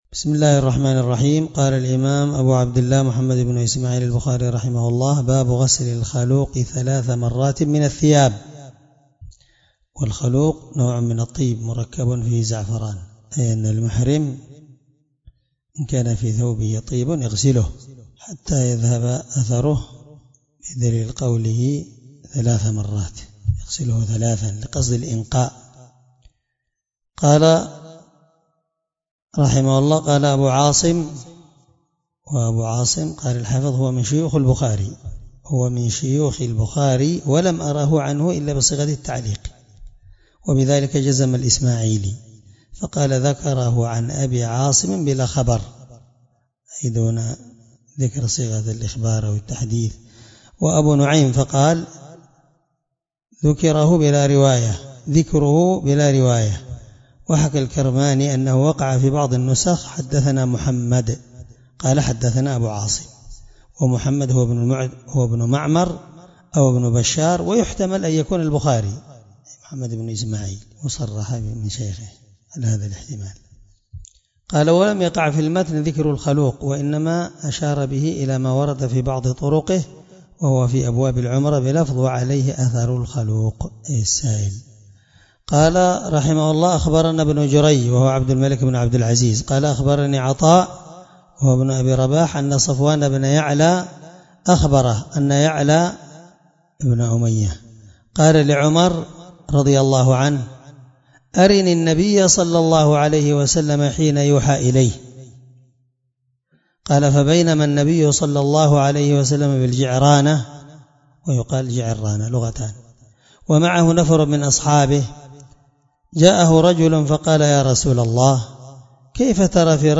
الدرس 9شرح كتاب الحج حديث رقم(1536 )من صحيح البخاري